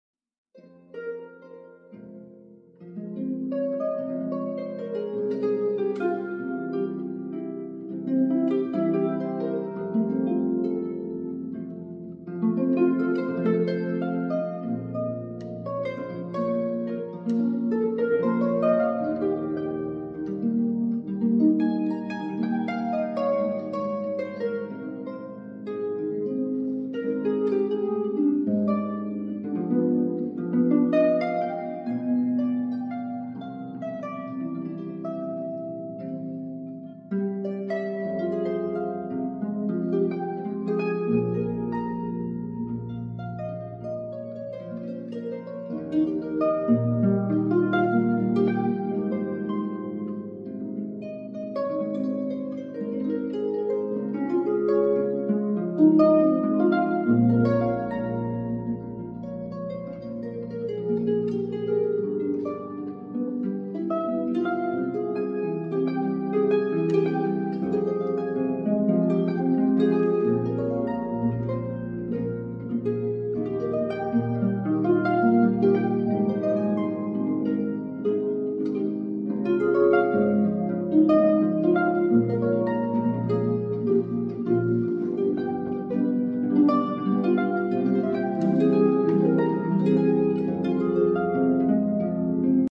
on Harp